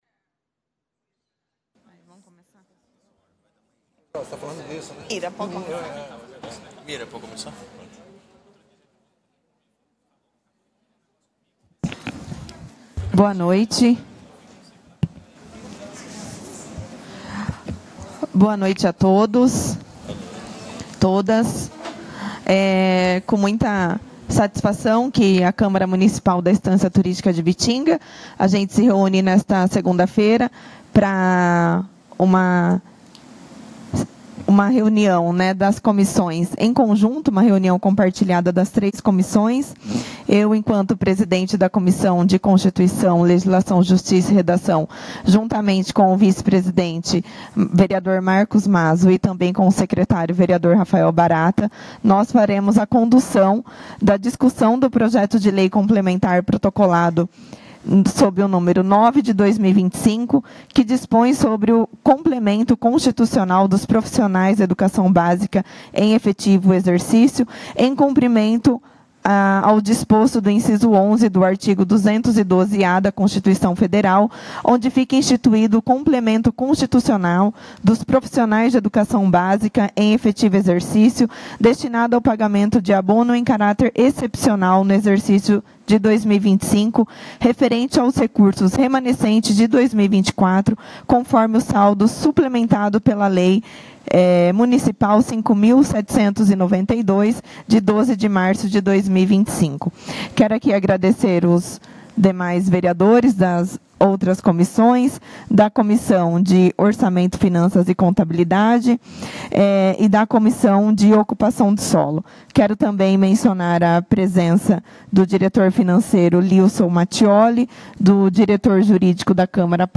Reunião comissões em 14/04/2025